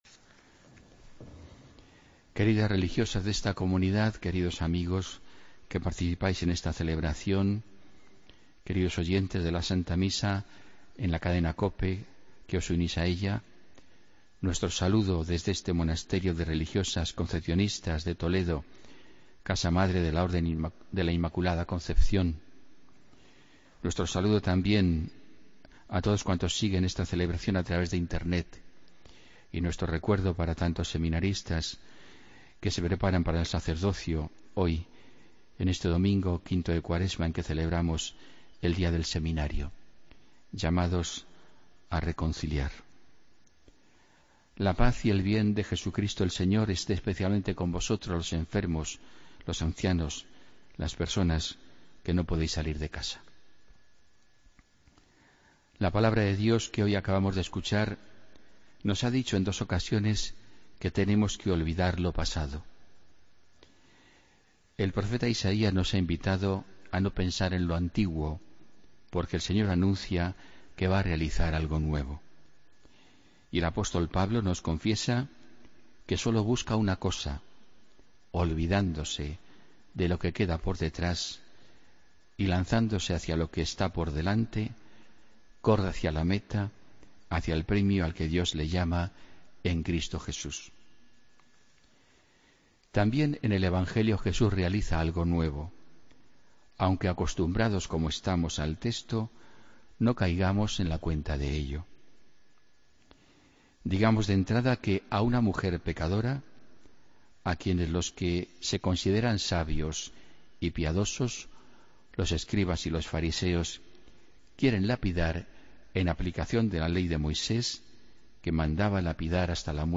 Homilía del domingo 13 de marzo de 2016